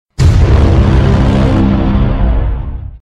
Короткий мощный звук для сообщения в Телеграм